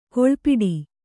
♪ kōḷpiḍi